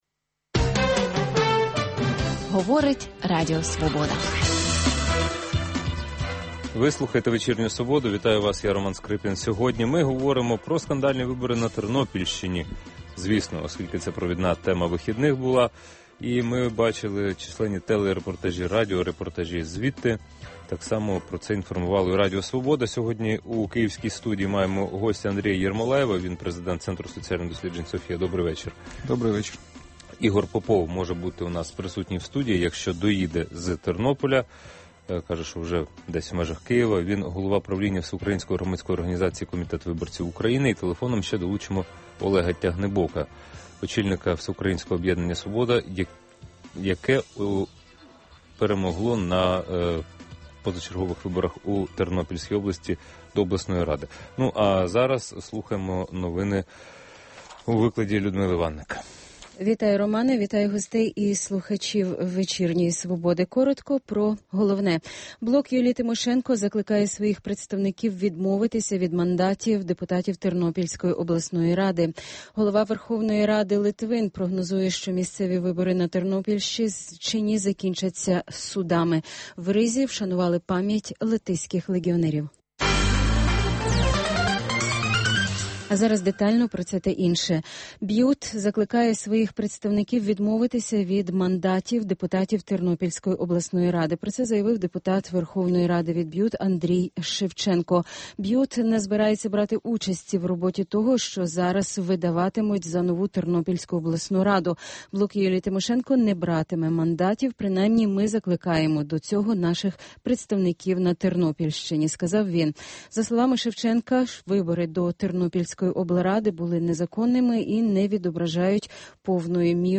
Інтелектуальна дуель у прямому ефірі. Дискусія про головну подію дня, що добігає кінця.